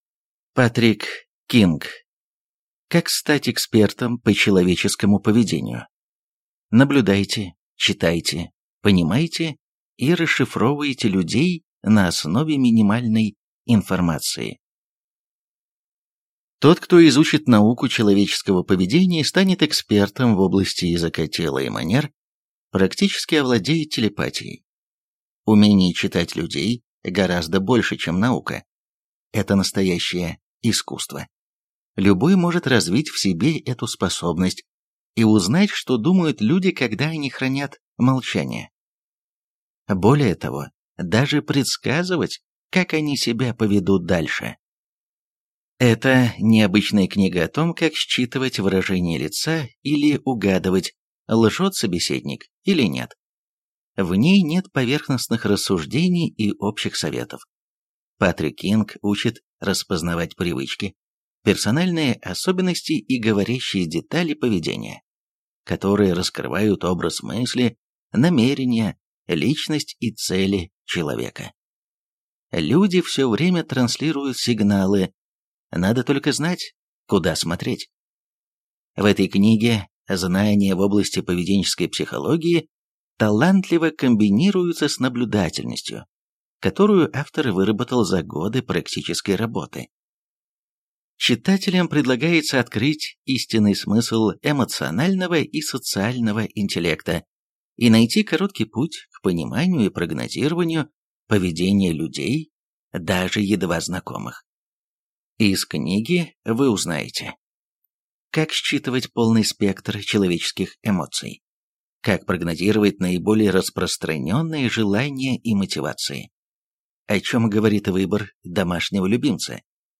Аудиокнига Как стать экспертом по человеческому поведению. Наблюдайте, читайте, понимайте и расшифровывайте людей на основе минимальной информации | Библиотека аудиокниг